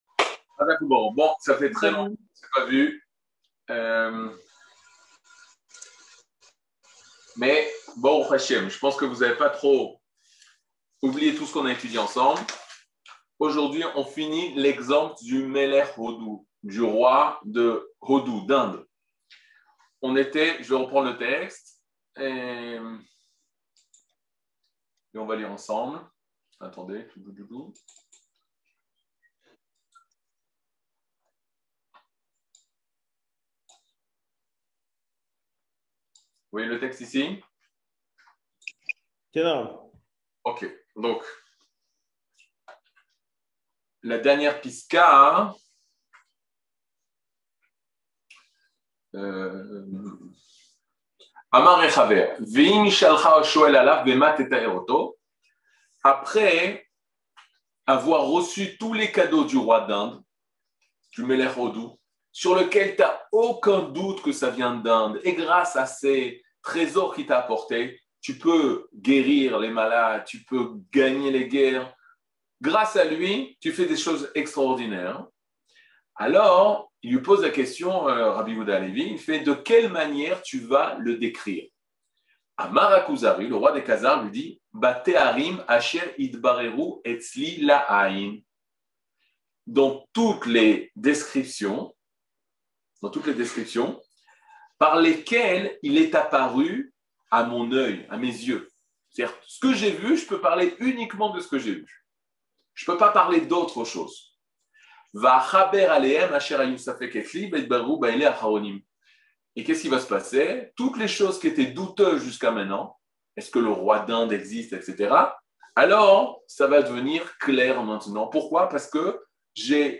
Catégorie Le livre du Kuzari partie 22 01:00:56 Le livre du Kuzari partie 22 cours du 16 mai 2022 01H 00MIN Télécharger AUDIO MP3 (55.79 Mo) Télécharger VIDEO MP4 (144.41 Mo) TAGS : Mini-cours Voir aussi ?